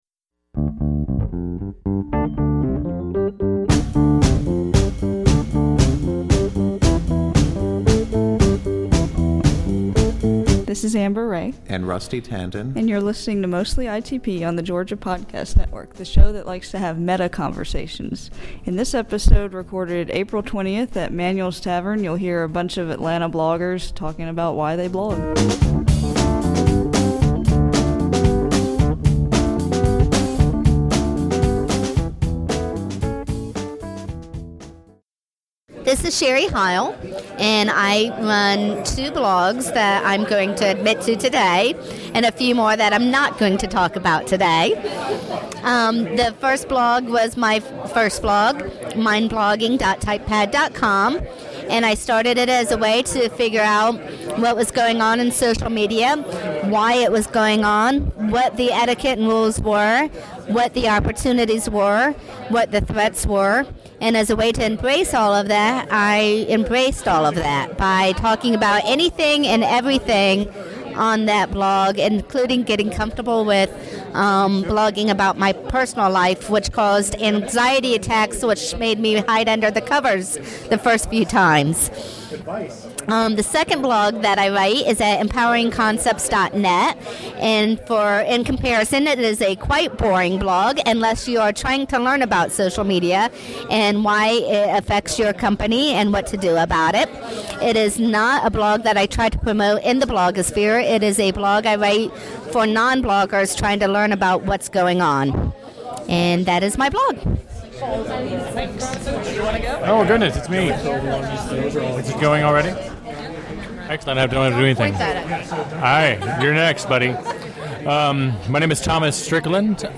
Show notes For the second podcast recorded the night of my blogiversary party at Manuel's, we passed the recorder around the table so everyone could talk for a few minutes about their blog, the benefits of blogging, and all that jazz.